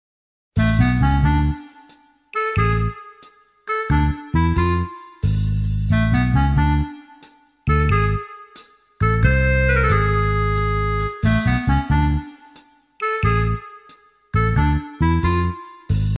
Ultrasonic mouse repeller#ultrasound#scared#get away#tiktok#foryou#fyp sound effects free download